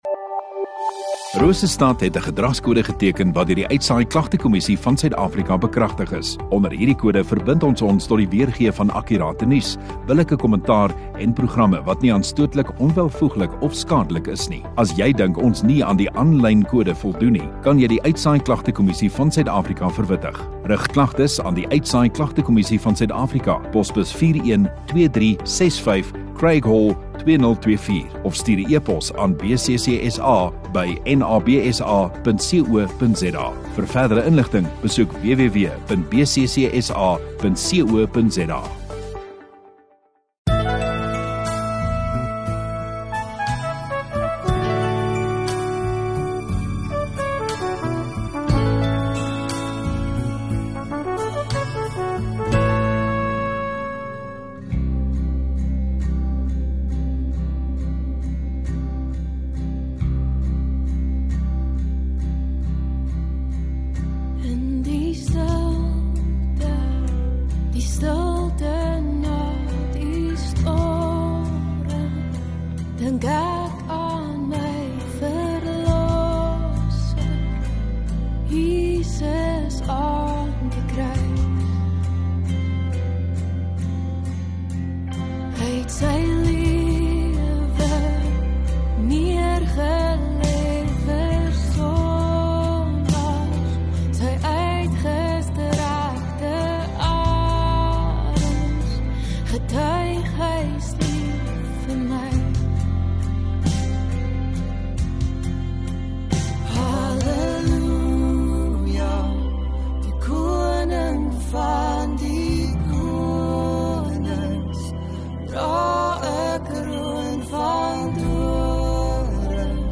8 Feb Saterdag Oggenddiens